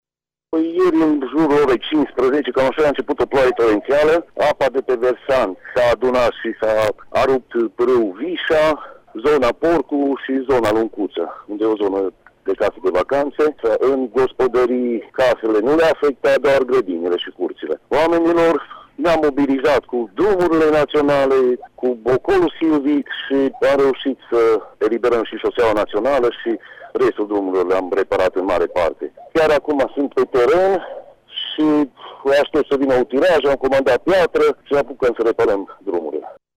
Potrivit primarului localităţii, Marius Lircă, viitura a blocat câteva drumuri comunale şi o parte dintr-un drum naţional. Lircă spune că s-a intervenit imediat cu utilajele primăriei ajutate de cei de la Drumuri Naţionale şi Romsilva şi că se lucrează şi în acest moment pentru repararea drumurilor: